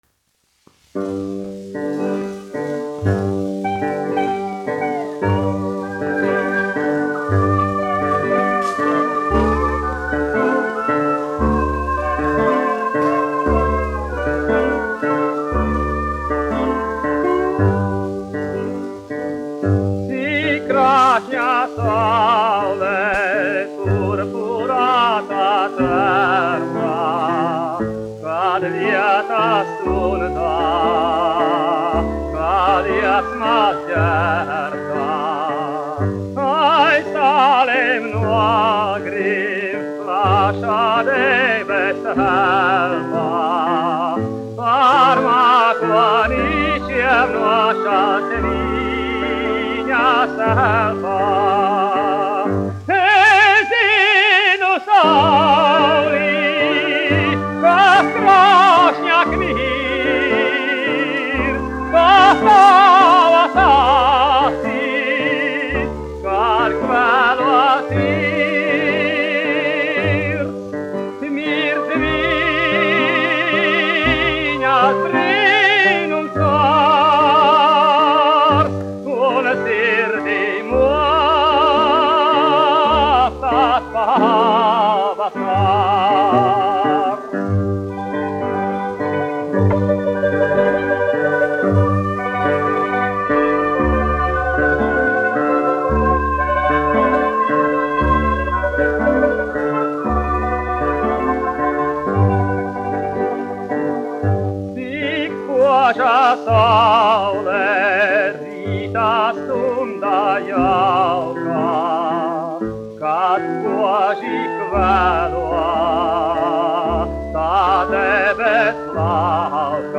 Priednieks-Kavara, Artūrs, 1901-1979, dziedātājs
1 skpl. : analogs, 78 apgr/min, mono ; 25 cm
Populārā mūzika -- Itālija
Dziesmas, neapoliešu
Skaņuplate